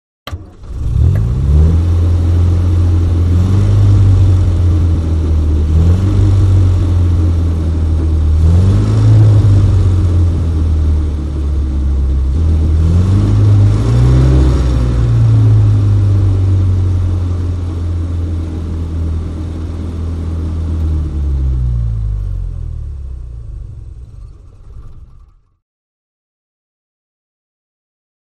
Aston Martin; Start / Idle / Off; Start With Sharp Pop, Engine Revs, Slow Wind-down With Stop. Deep Motor Sound With Fans And Quiet Rattles. Close Perspective. Sports Car, Auto.